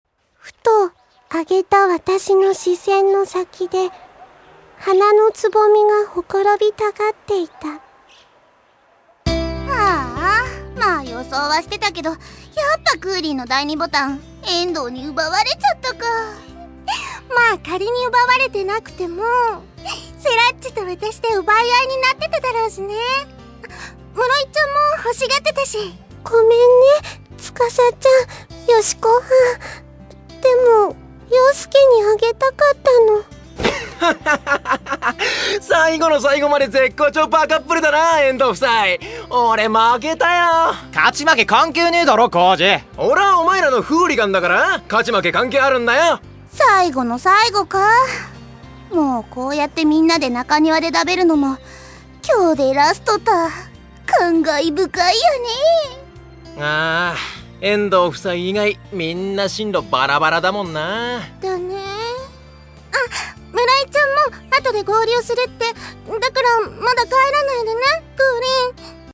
シリウス初のおたのしみ＆ファンディスク的ドラマＣＤがついに発売！